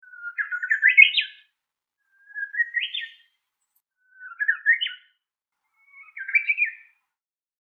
ウグイス練習中2.mp3